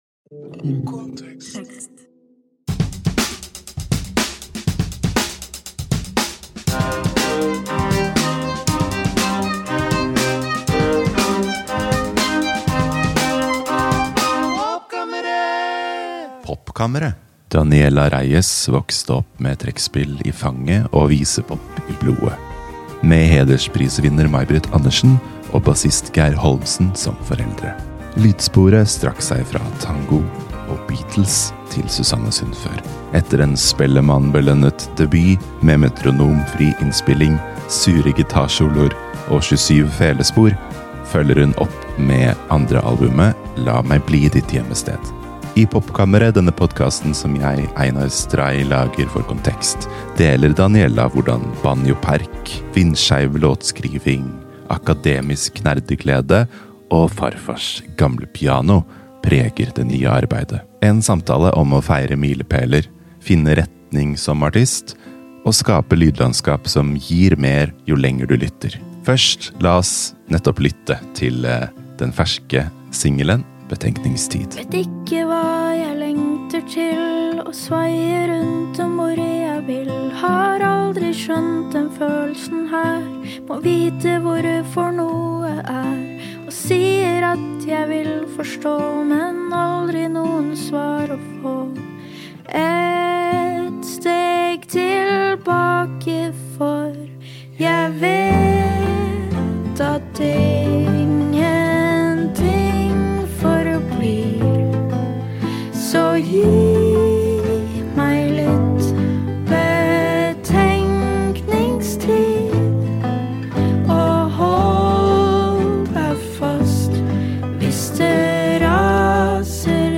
En samtale om å feire milepæler, finne retning som artist – og skape lydlandskap som gir mer jo lenger du lytter.